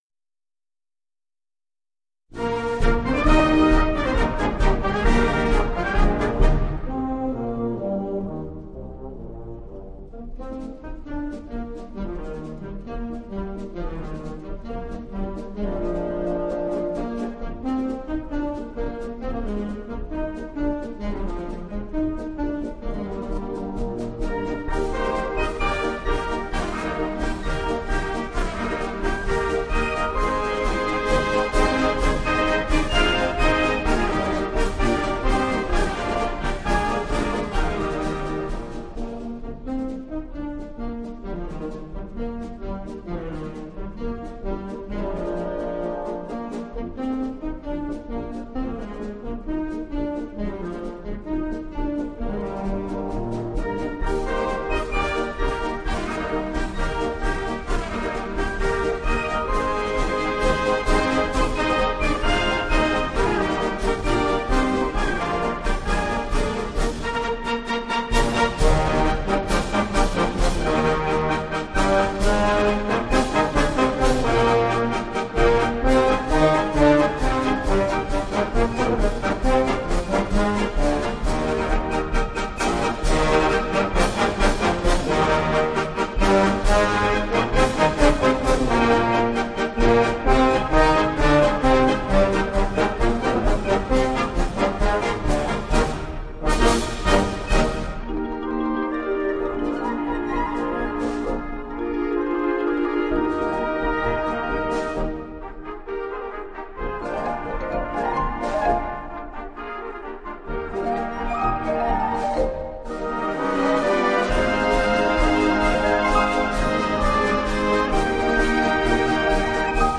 Gattung: Amerikanischer Militärmarsch
Besetzung: Blasorchester